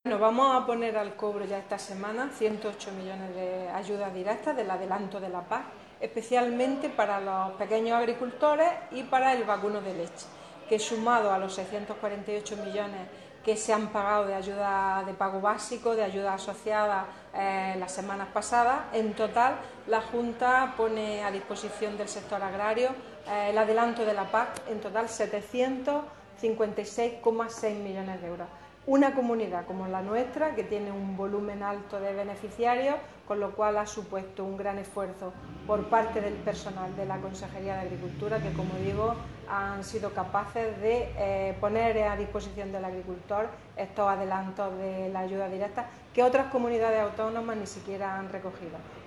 Declaraciones de Carmen Ortiz sobre el adelanto de las ayudas directas de la PAC